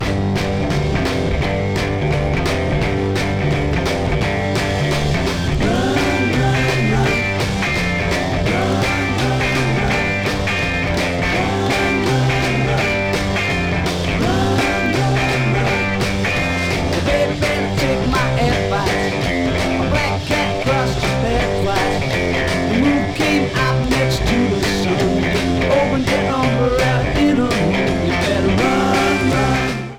Mostly true stereo